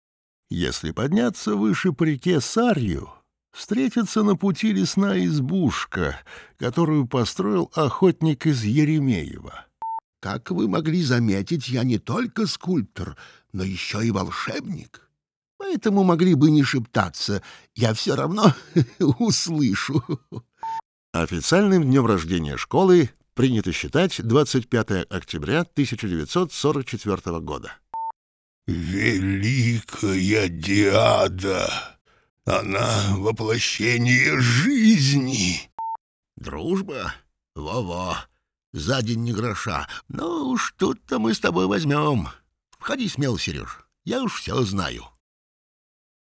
Тракт: Микрофон TLM 103,Sennheiser MKH 416-P48U3, карта YAMAHA 03,